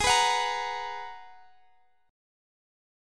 Listen to Abm9 strummed